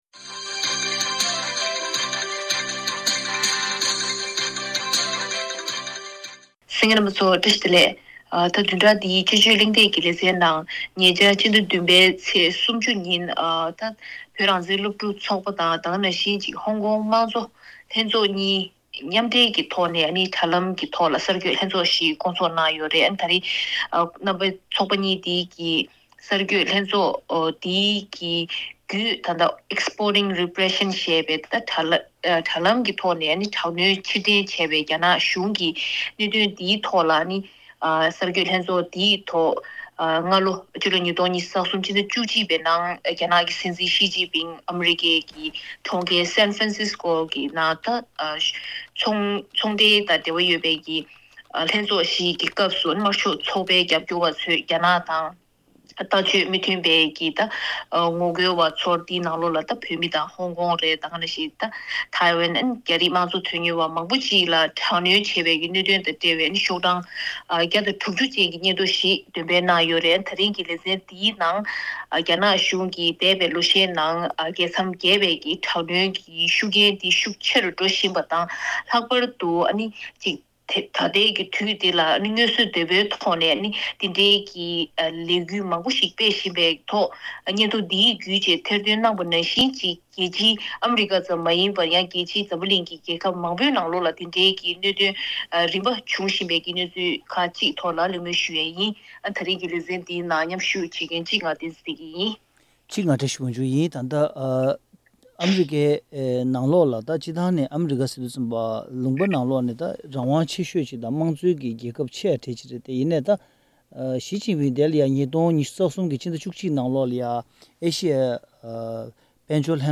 དཔྱད་གཞིའི་གླེང་མོལ་ཞུས་པ་འདི་གསན་རོགས་གནང་།།